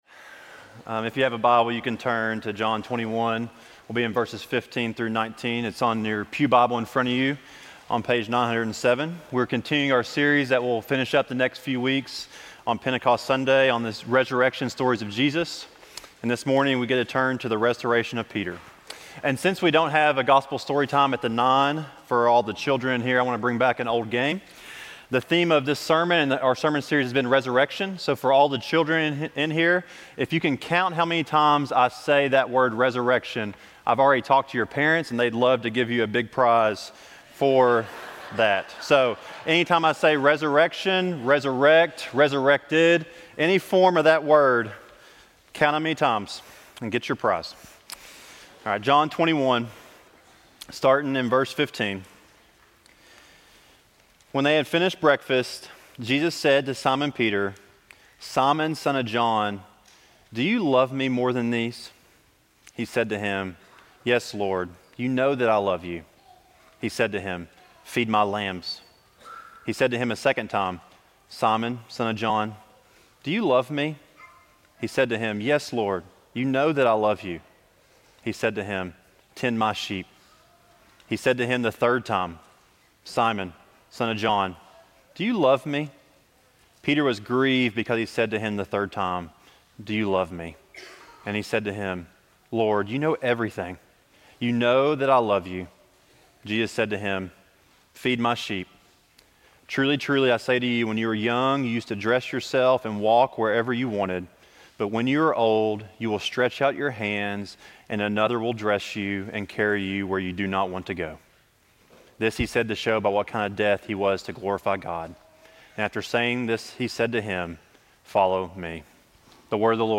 Sermons recorded during worship at Tates Creek Presbyterian Church (PCA) in Lexington, KY